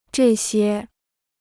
这些 (zhè xiē) Dictionnaire chinois gratuit